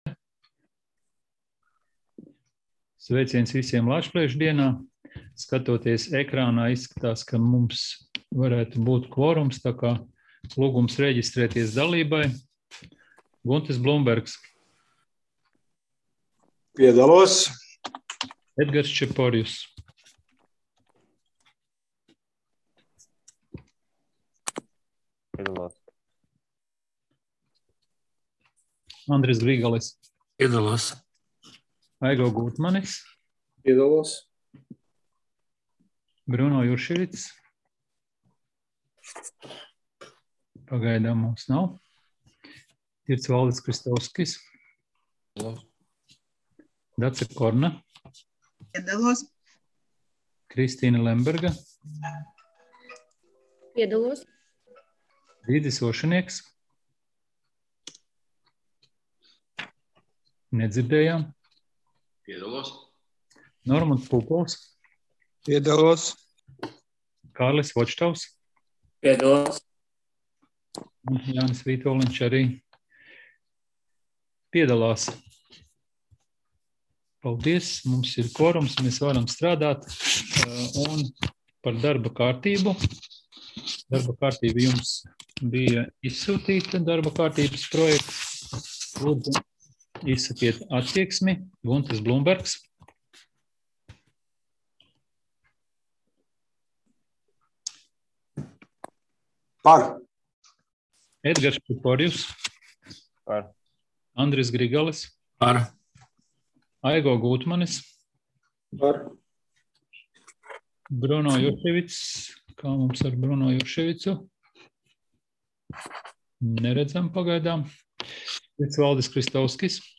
Domes sēdes 29.05.2020. audioieraksts